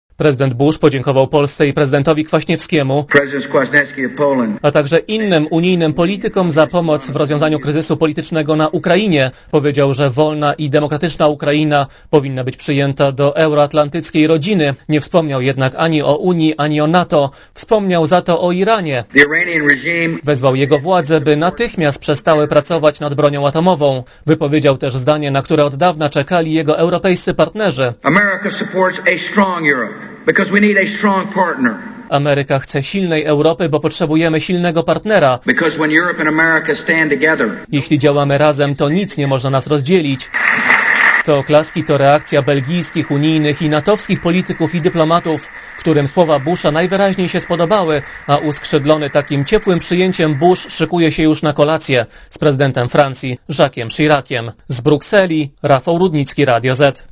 Bush przemawia w Brukseli, gdzie rozpoczął swą podróż po Europie.
bush_-_przemowienie_w_ue.mp3